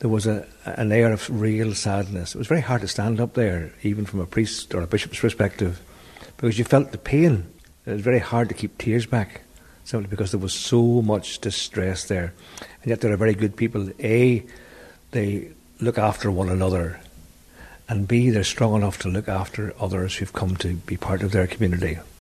Bishop Donal McKeown led the service in Buncrana………………